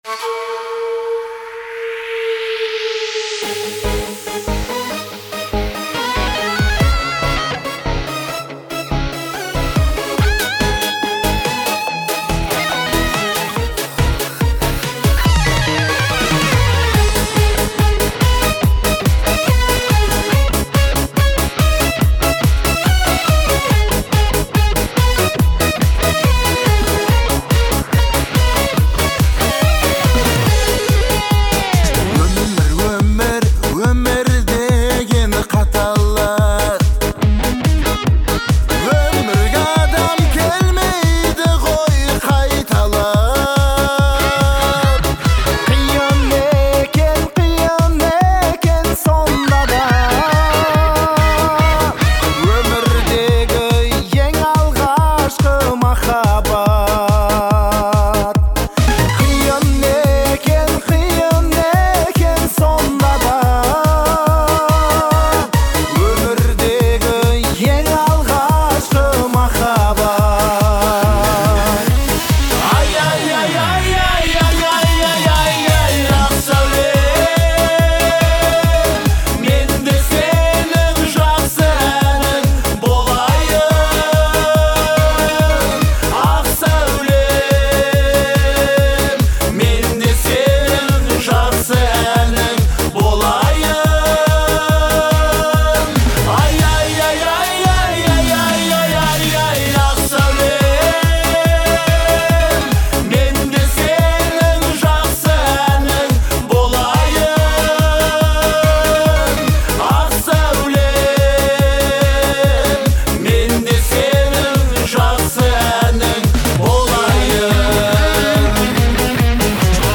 это душевная казахская песня в жанре поп